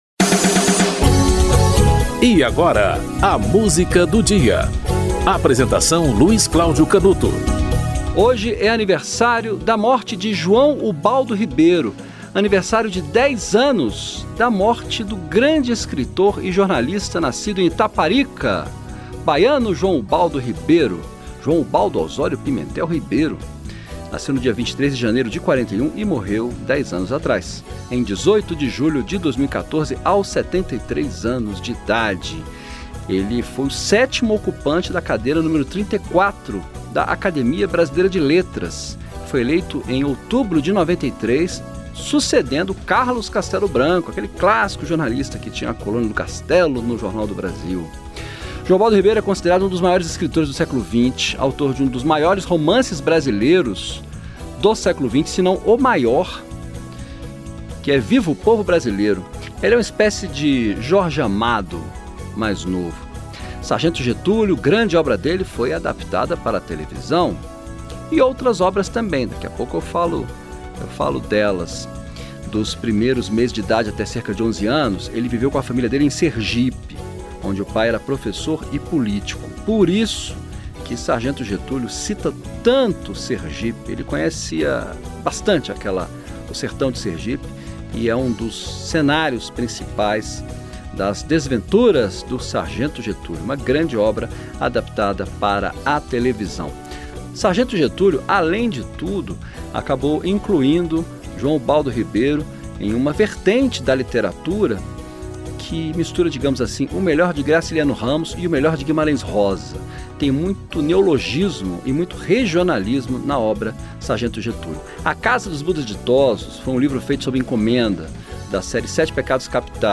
Produção e apresentação